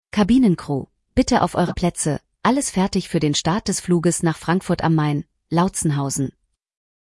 CrewSeatsTakeoff.ogg